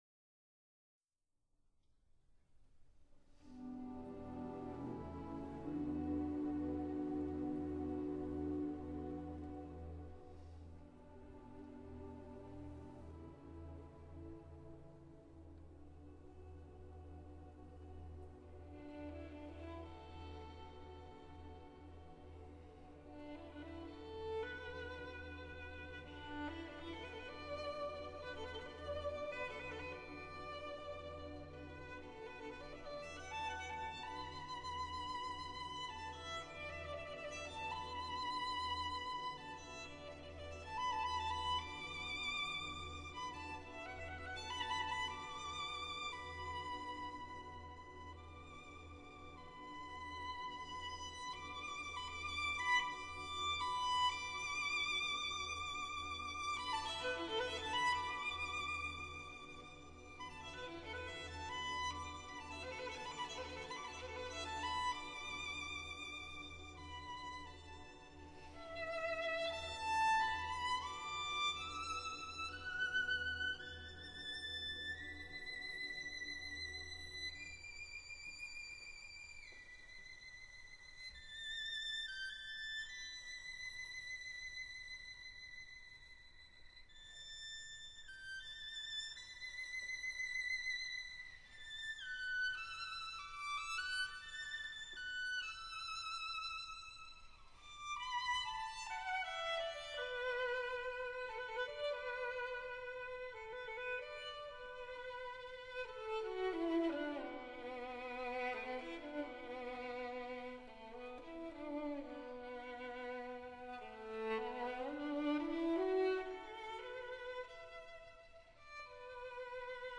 The Lark Ascending, by Ralph Vaughn Williams, is an exquisitely beautiful and haunting piece of music that perfectly captures the joy of returning from war and the pain of what had to be endured.